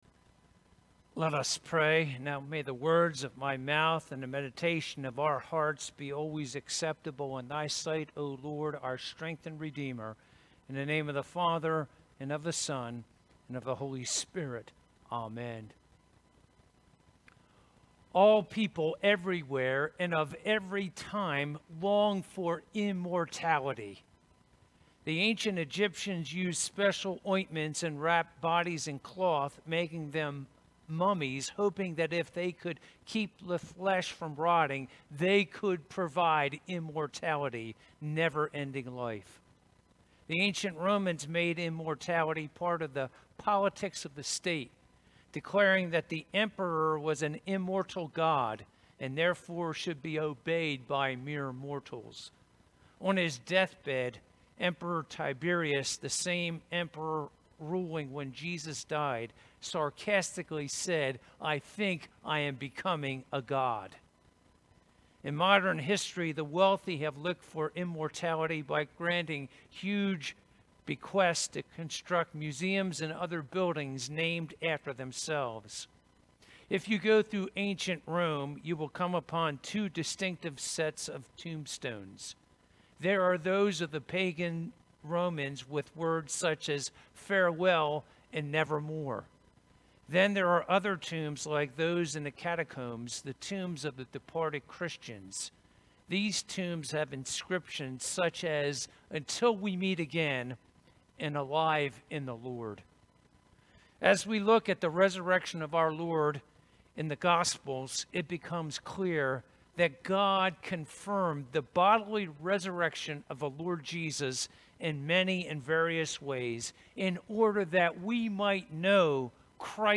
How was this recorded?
Service Type: Easter Sunday